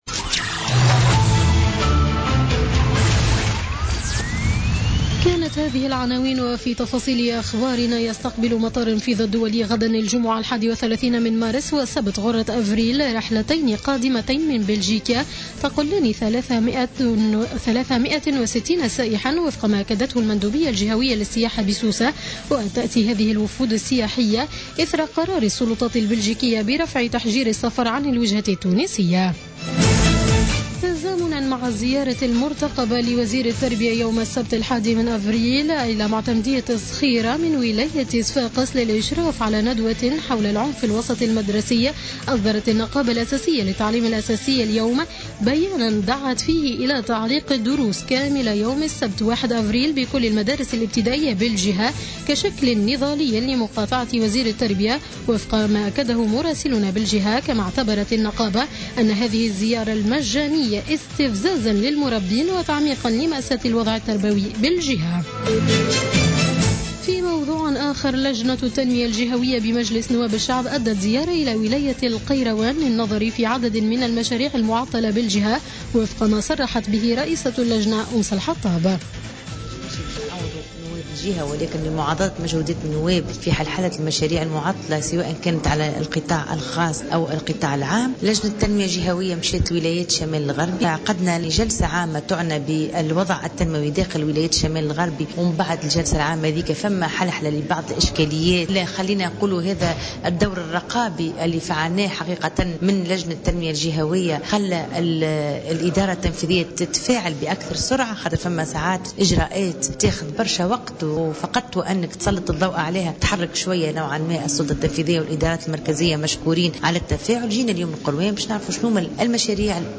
نشرة أخبار السابعة مساء ليوم الخميس 30 مارس 2017